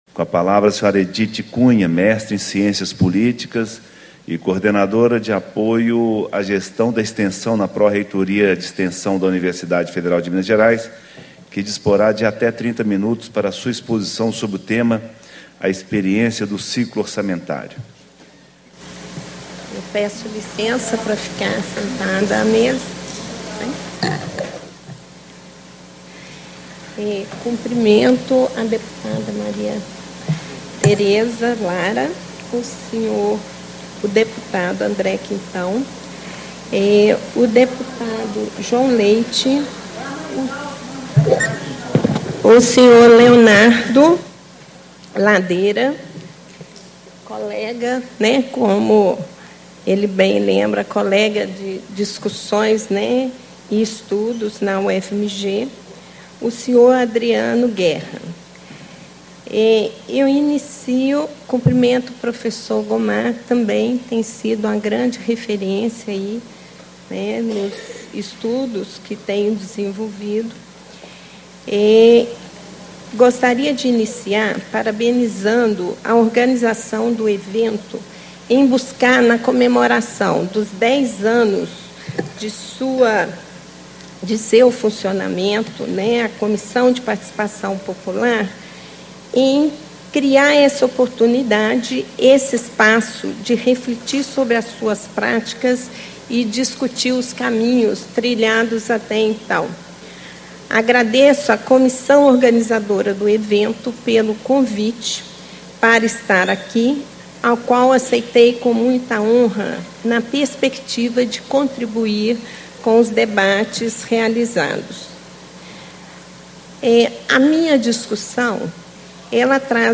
Ciclo de Debates 10 anos da Comissão de Participação Popular
Palestra